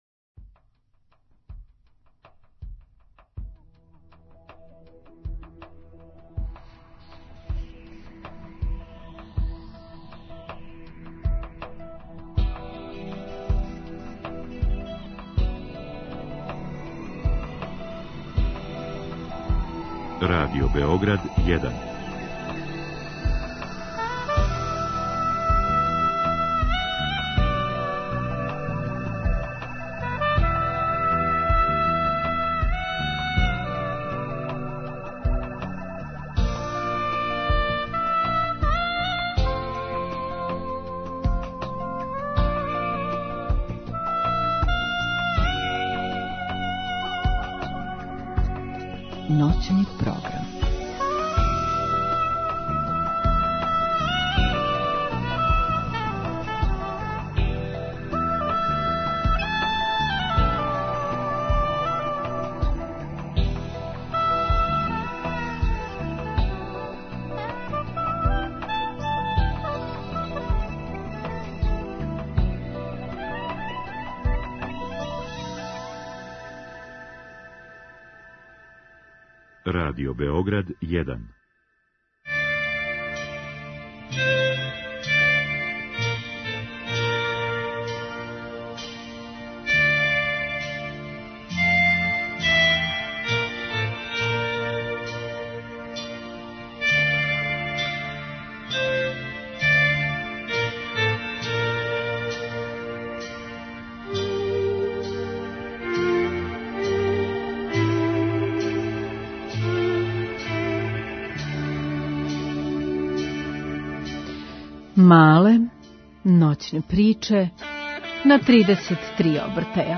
У Малим ноћним причама на 33 обртаја чућете композиције настајале током прошле године. Сазнаћете да ли су 'опевали' лична искуства како би створили најупечатљивију музику и кога су све популарни музичари угостили на својим албумима.